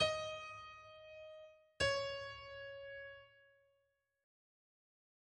Comecemos com um motivo simples.
Aqui, exceto pela complexa tonalidade de Si Maior (sustenidos demais), tudo parece claro e simples.
Adicionalmente, podemos afirmar que uma provável harmonia implícita seria a de tônica para dominante.